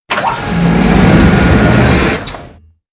CHQ_VP_ramp_slide.ogg